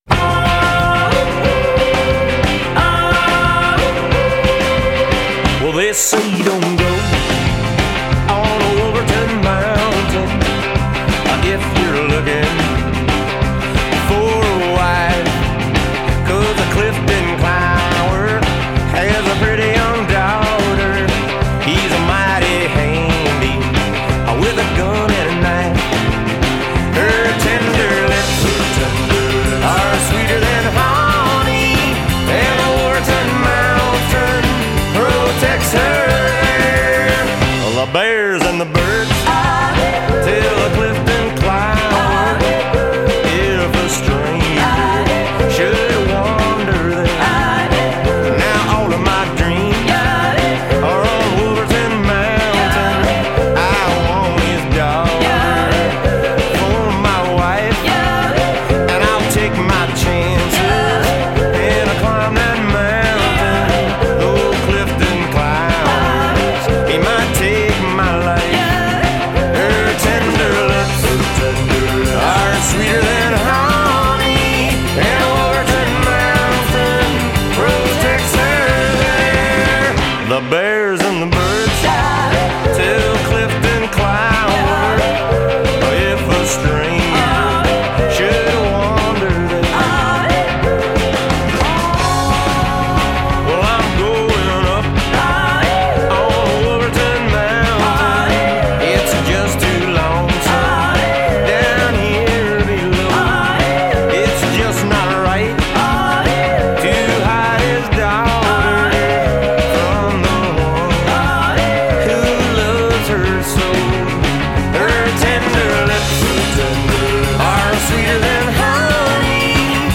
hillbilly surf rock